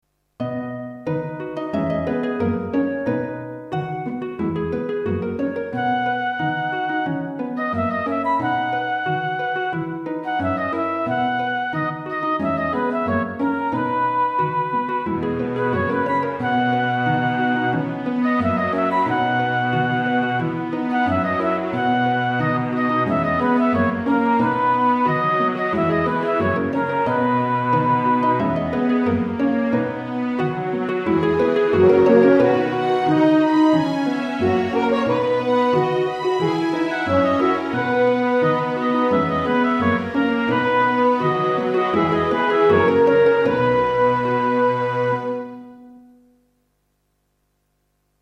klassiek
Een tribuut aan de filmmuziek van die oude jeugdseries.